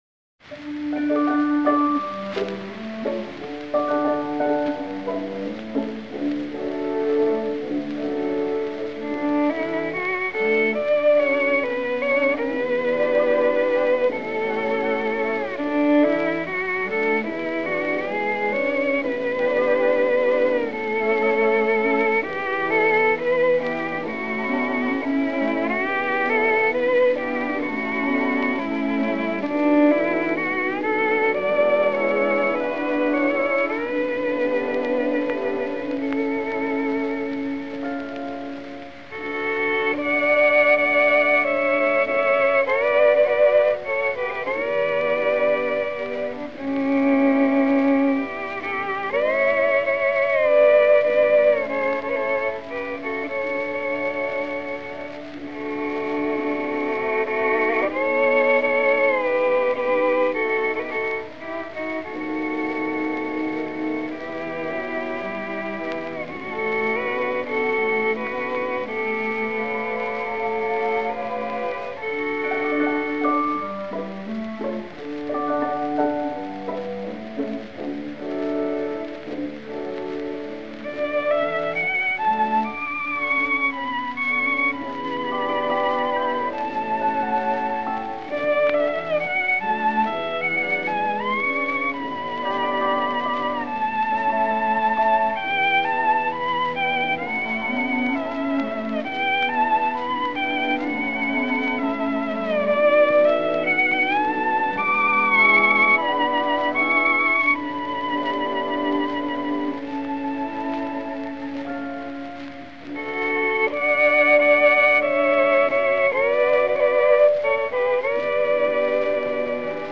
Violin + Piano   PDF